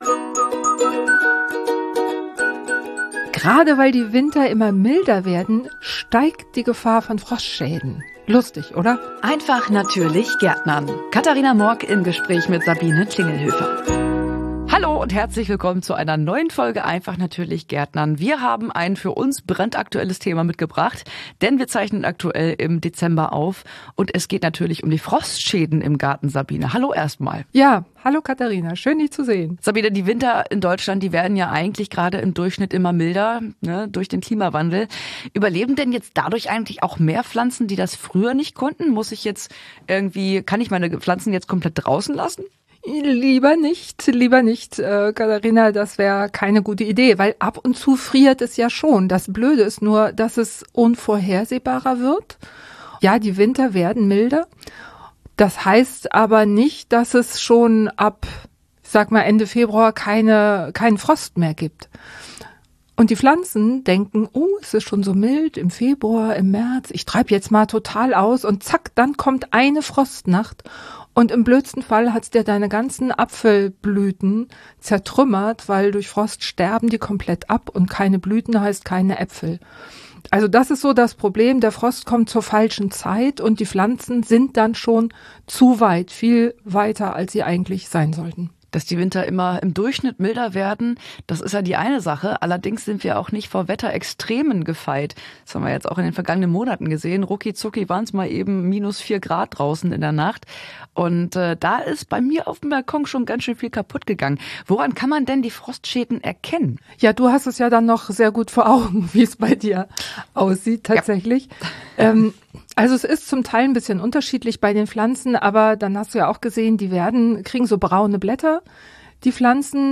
Hier geht es um das praktische und einfache Gärtnern mit der Natur für Gartenanfänger und auch für Fortgeschrittene. Im Gespräch stellen wir einfache Möglichkeiten vor, wie man selbst kompostieren kann, oder wie man am einfachsten ein eigenes Gemüsebeet anlegt.